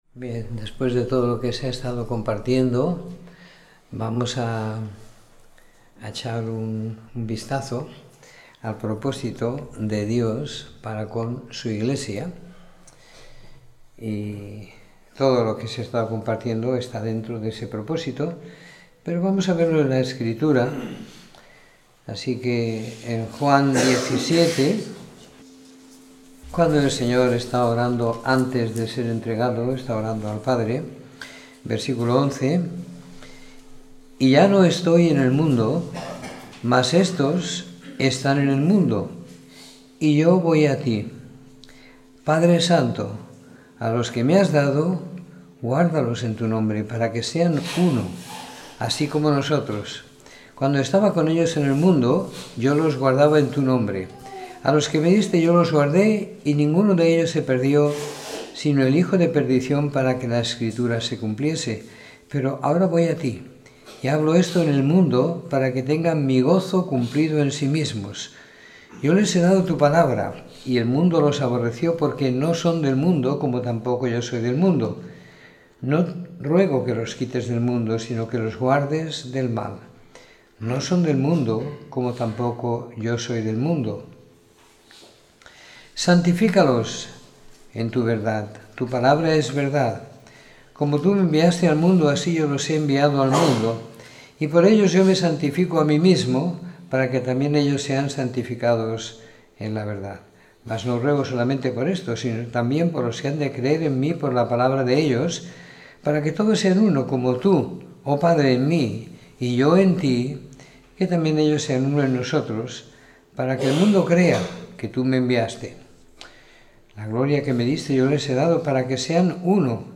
Domingo por la Tarde . 05 de Marzo de 2017